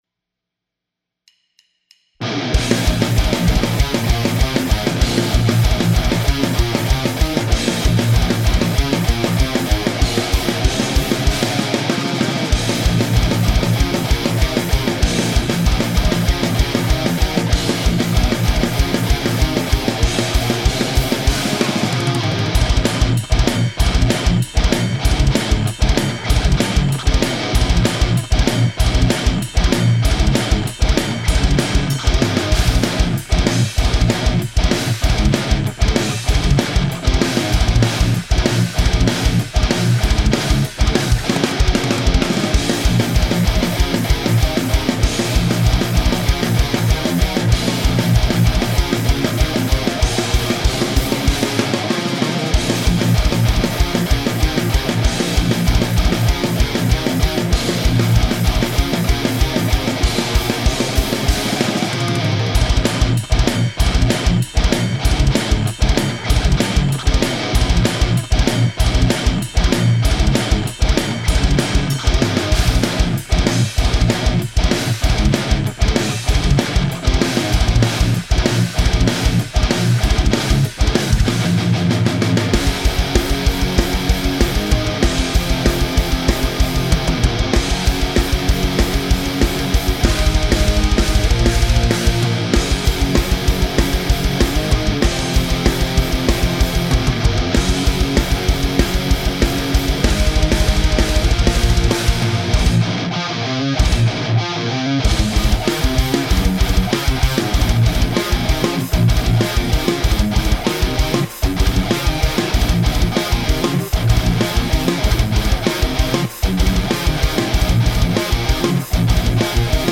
Reamping test please help
I premixed the drums and we're now recording the guitars through a POD with a DI in parallel.
We used a Gibson SG Standard guitar with original pickups, for a kind of raw/ rock'n'roll tone. The drums have no samples to them except the kick drum which is a mixture of the mic (Beyer M88) and Sneap sample for attack.
We recorded in a big room and I used two room mics.